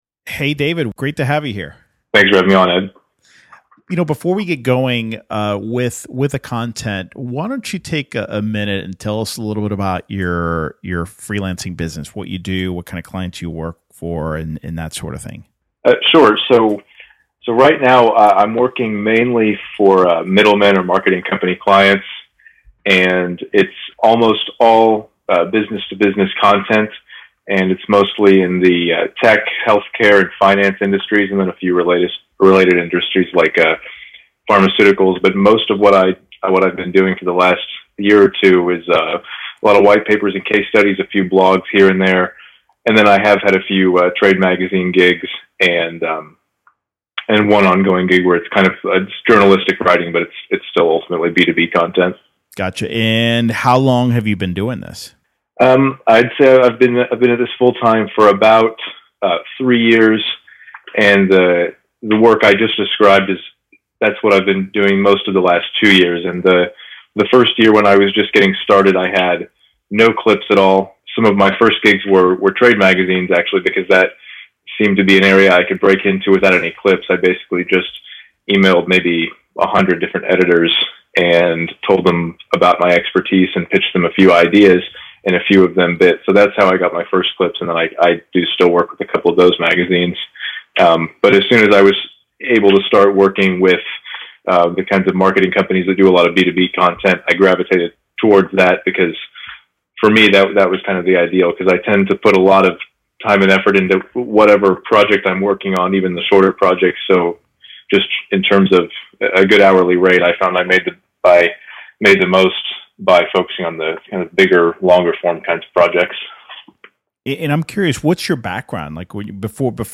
That's what I asked him in a recent interview.